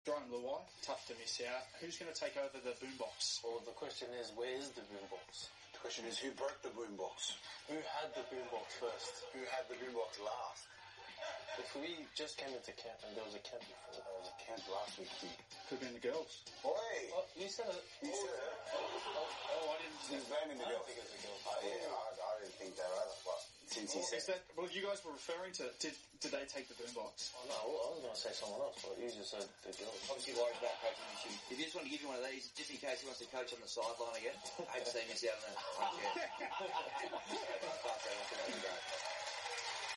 Stephen Crichton and Brian To'o return with their signature banter — and this time, Cooper Johns is on the mic as the interviewer!